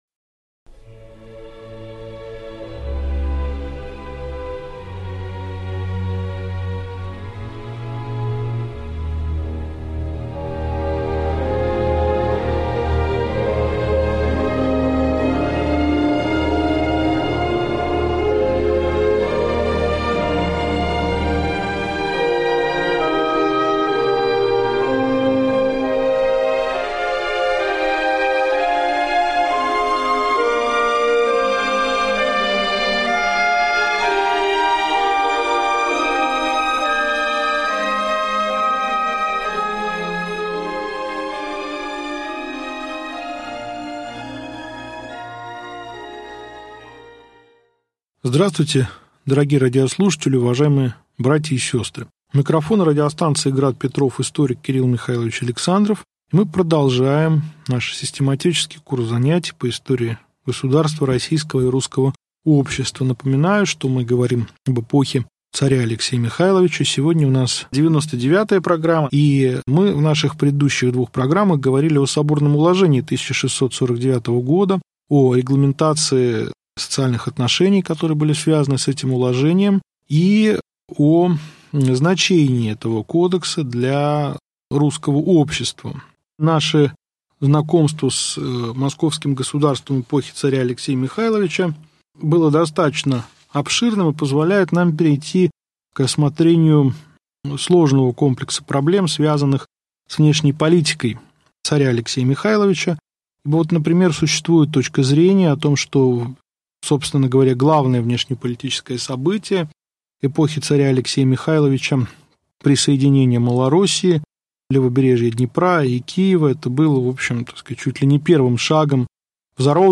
Аудиокнига Лекция 99. Внешняя политика царя Алексея Михайловича. Русско-шведская и русско-польская войны | Библиотека аудиокниг